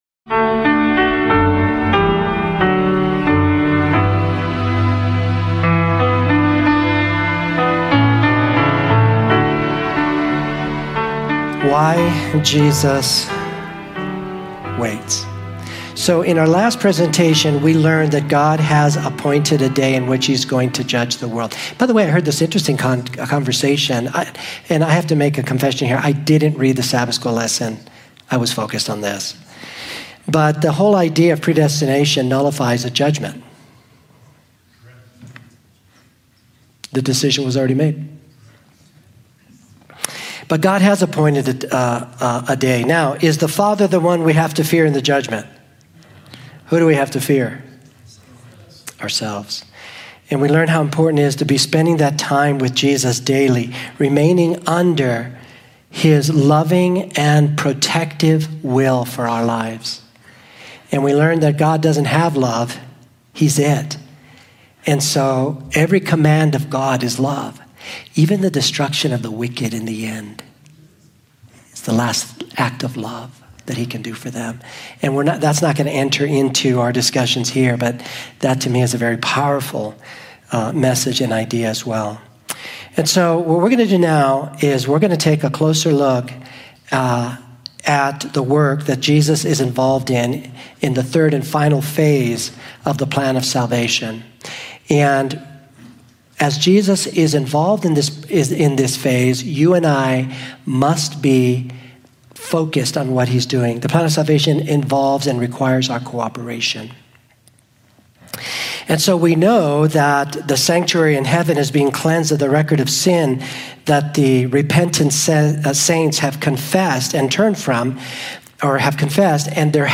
Discover how the sanctuary model reveals the gospel’s deeper truths and how God’s judgment is an act of love, not fear. This sermon explores self-examination, transformation, and the patient process of spiritual growth as we prepare for Christ’s return.